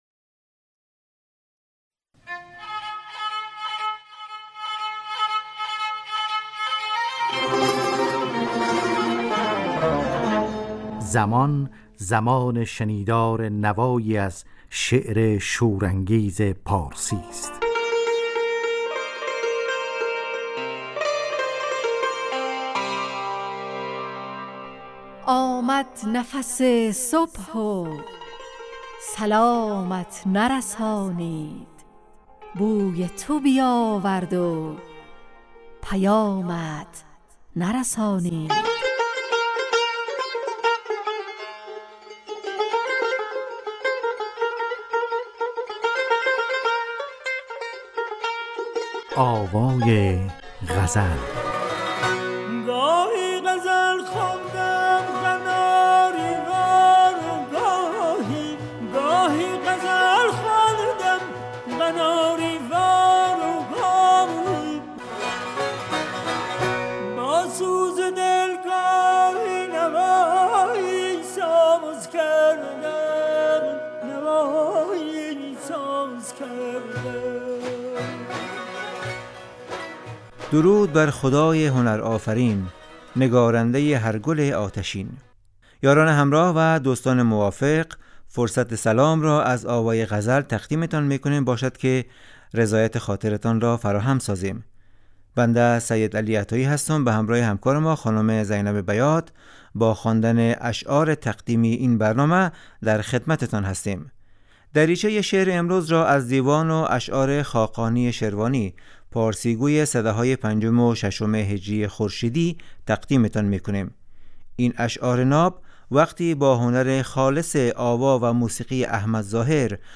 آوای غزل نواهنگ رادیویی در جهت پاسداشت زبان و ادبیات فارسی . خوانش یک غزل فاخر از شاعران پارسی گوی و پخش تصنیف زیبا از خوانندگان نامی پارسی زبان .